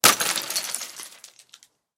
На этой странице собраны разнообразные звуки сосулек — от нежного звона капель до резкого обрыва ледяных глыб.
Звук падающей сосульки с крыши или карниза дома